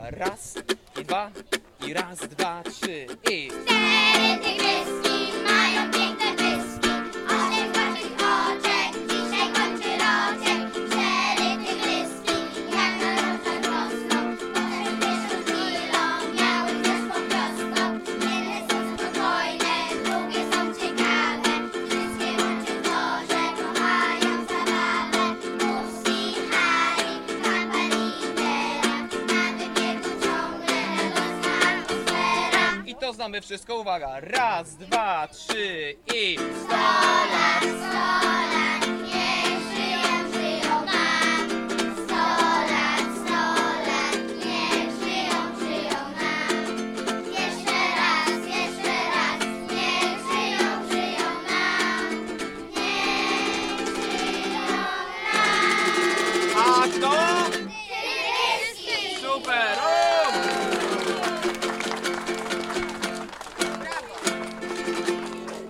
Jak na urodziny przystało – nie zabrakło muzycznych życzeń. Przygotował je półkolonijny zespół Rytmiczne Pazurki.